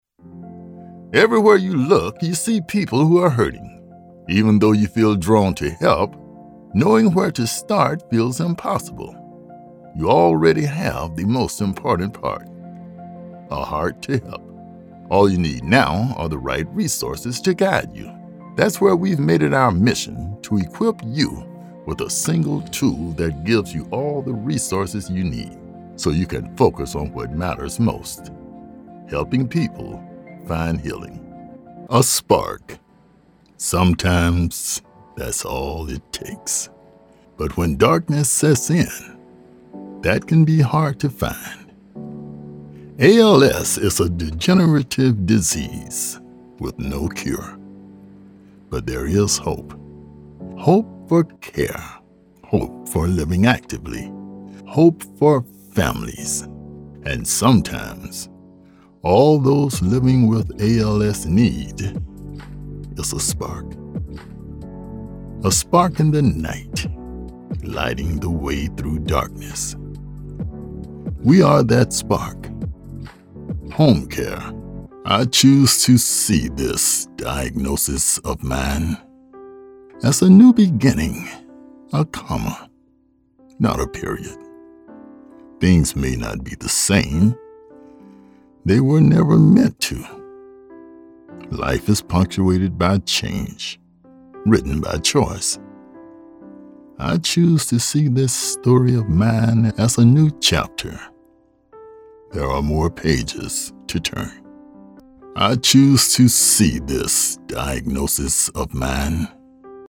Senior, Baritone, gravely, base, middle age, fatherly, storytelling, senior ,narrator, guy next door
NARRATIONS-Health Care Facilities- Conversational; Believable; Educational; Warm;Real
Gear: Whisper Room Recording Booth, Mac Workstation/Adobe Audition, Universal Audio Interface, Neumann TLM103 Mic
Style: Believable. Trustworthy. Sincere. Friendly. Fresh. Relatable, Authentic, Authoritative, Informative, Genuine, Gritty, Guy Next Door.
NEW HEALTH CARE COMMERCIAL DEMO.mp3